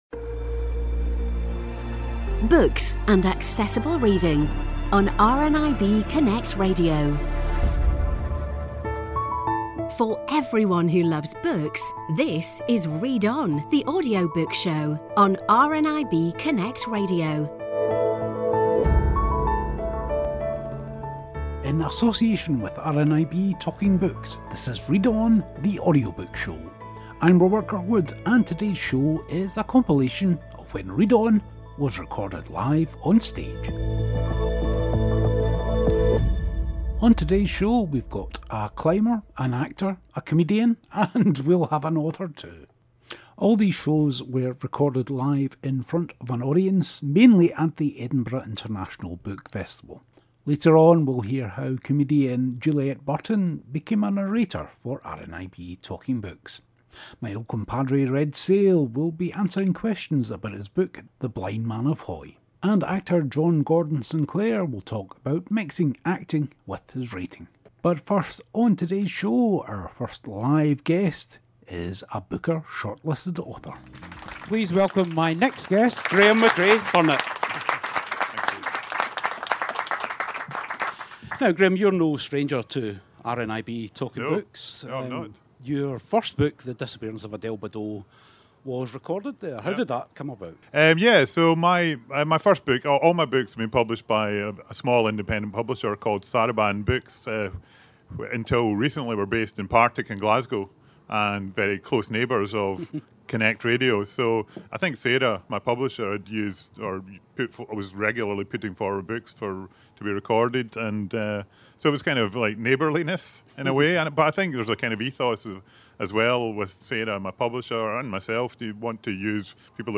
A compilation episode today of the times Read On was recorded live on stage in front of an audience.